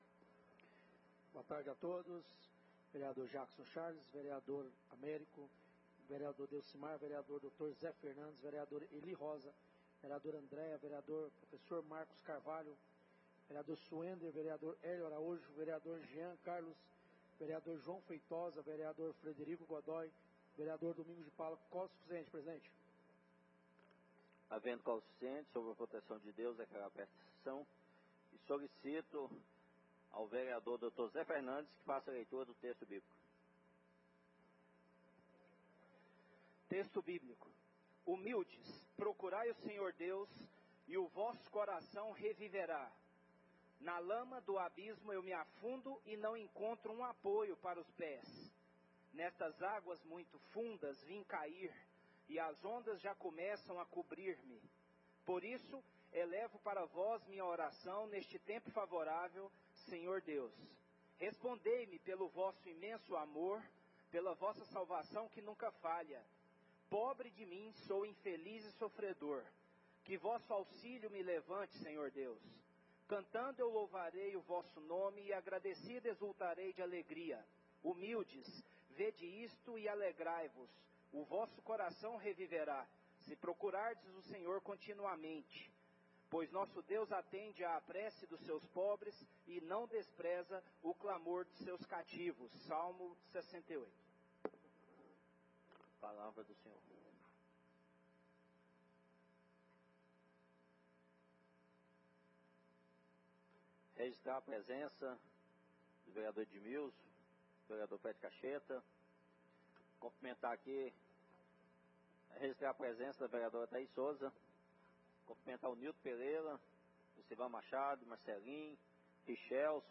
8° Extraordinária da 3° Sessão Legislativa da 19° Legislatura. Dia 18/07/23.